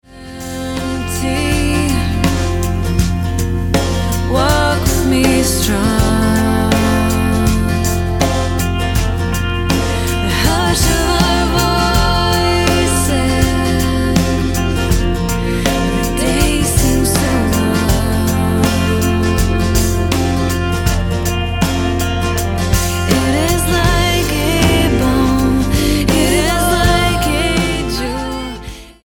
STYLE: Roots/Acoustic
signature harmonies and driving guitars